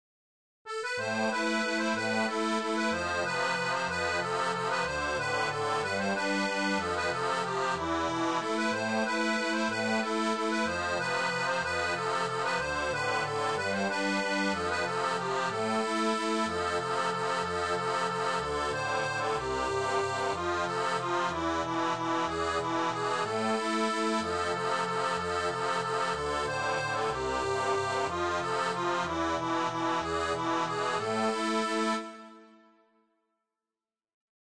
Chanson française